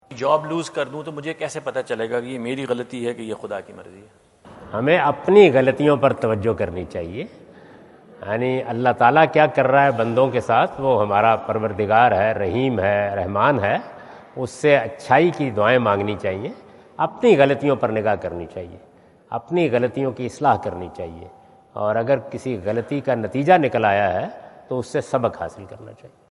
Javed Ahmad Ghamidi answer the question about "Losing job, how to know is it God’s will or my mistake?" During his US visit at Wentz Concert Hall, Chicago on September 23,2017.